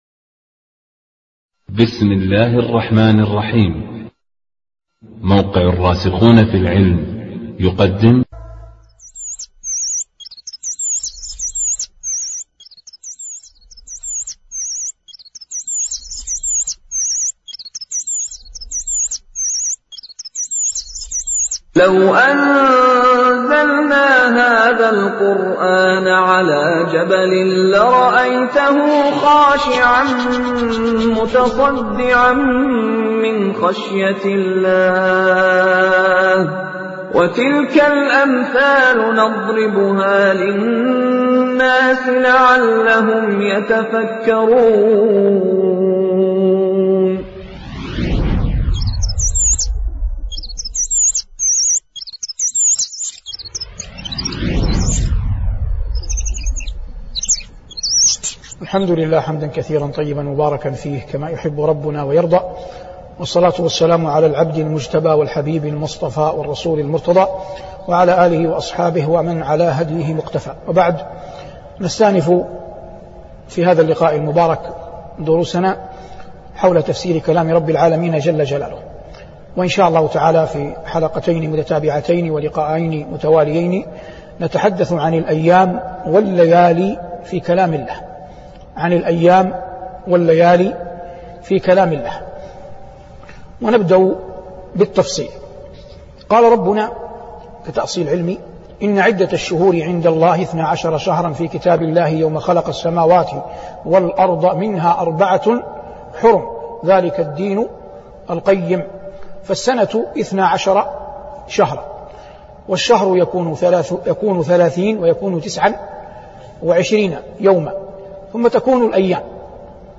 شبكة المعرفة الإسلامية | الدروس | الأيام والليالى فى كلام رب العالمين 1 |صالح بن عواد المغامسي